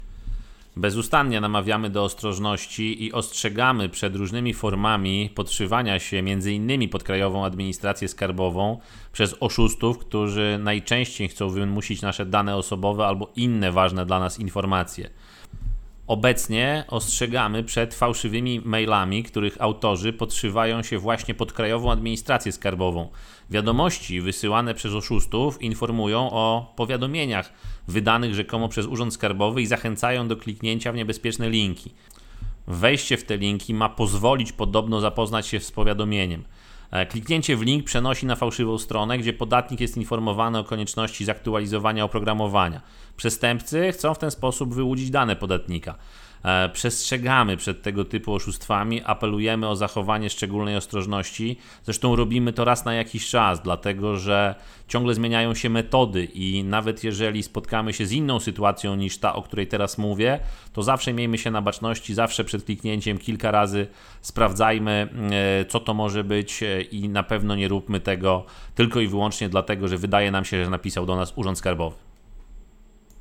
Uwaga! Oszuści podszywają się pod Krajową Administrację Skarbową (wypowiedź